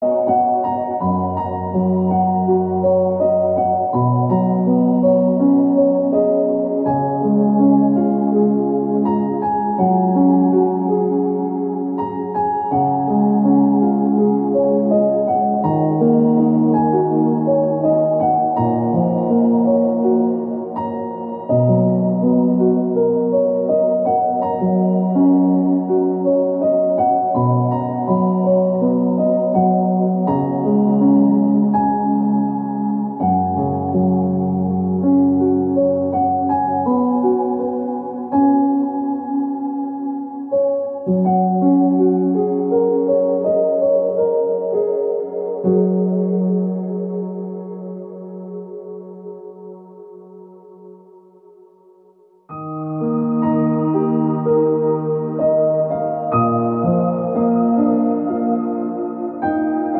Deep tissue massage tension release